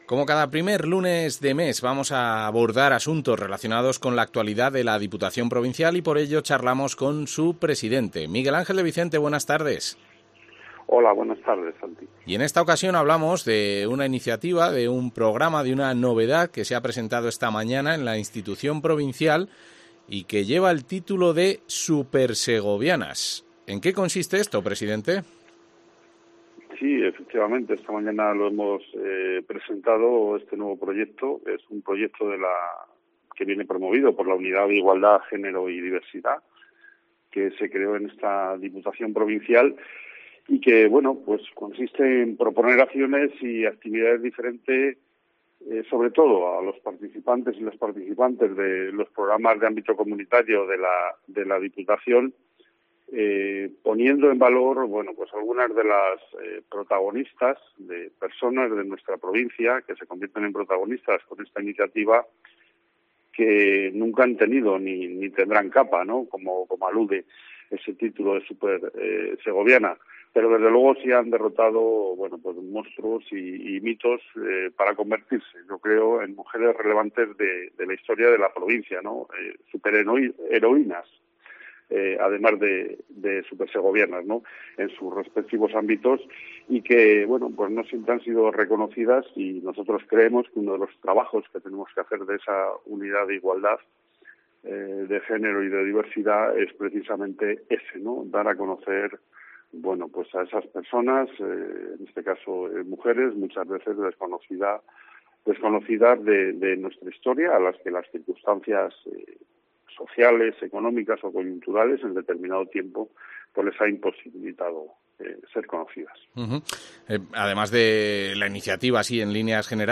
El presidente, Miguel Ángel de Vicente, explica la iniciativa de la Unidad de Igualdad, Género y Diversidad